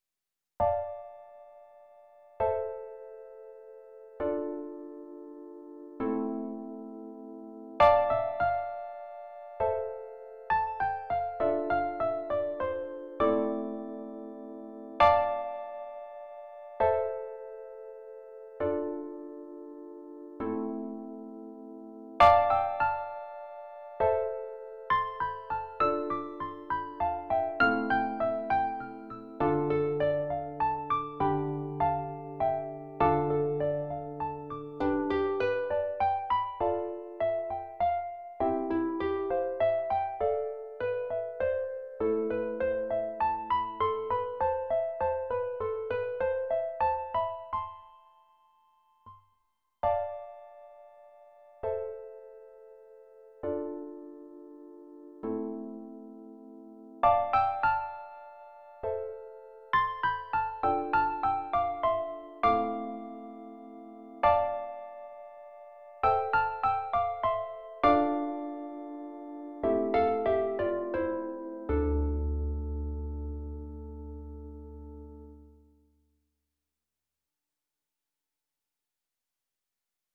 for two lever or pedal harps